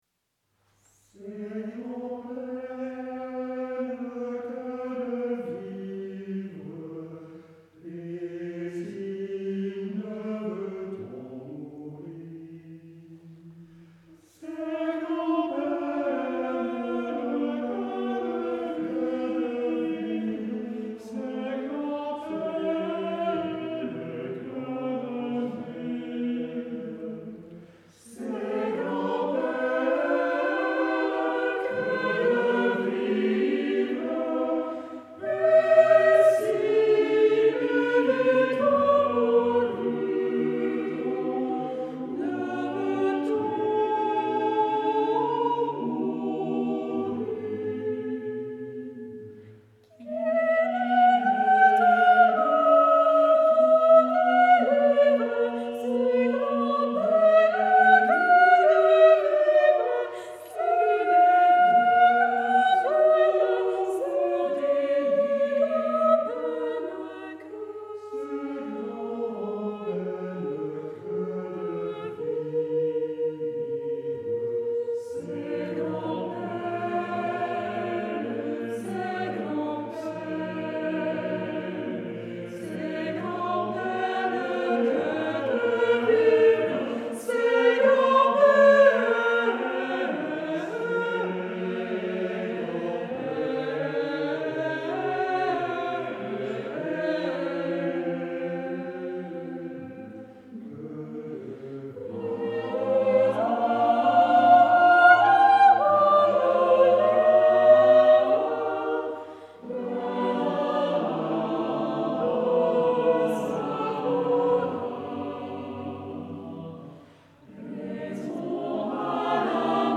Concert du 22 octobre 2017
Temple de Dombresson